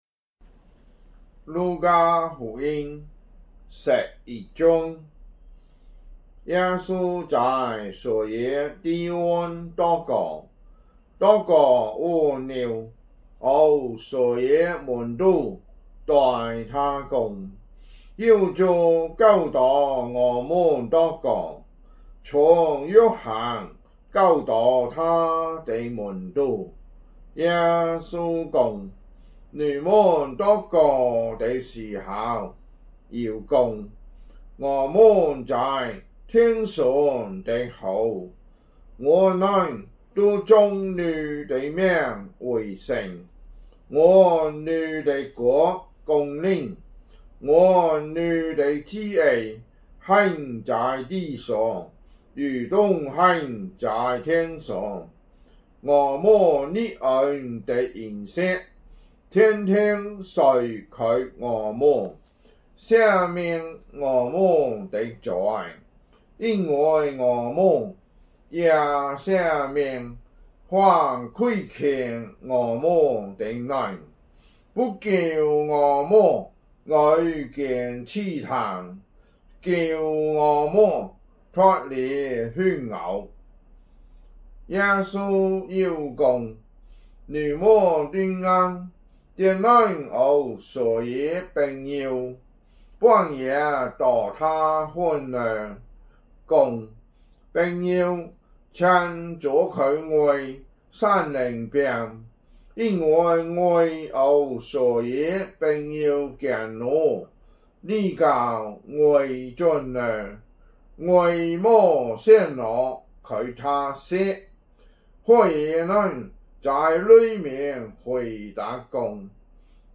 福州話有聲聖經 路加福音 11章